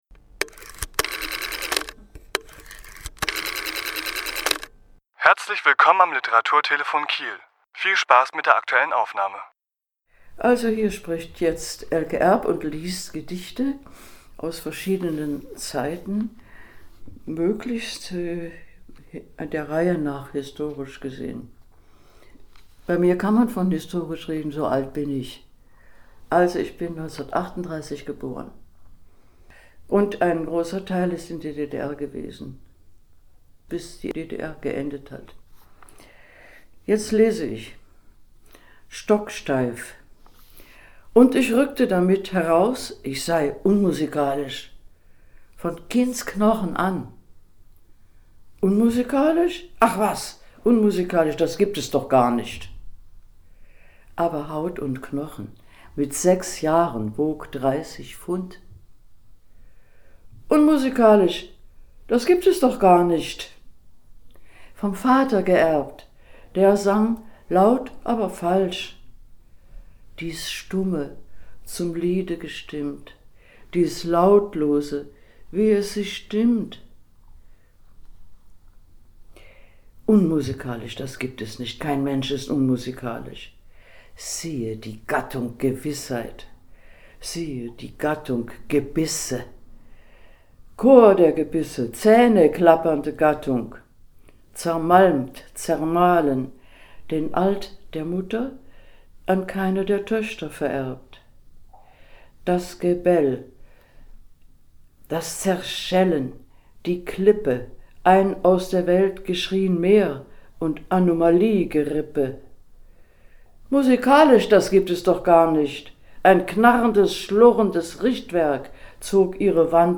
Autor*innen lesen aus ihren Werken
elke_erb_gedichte.mp3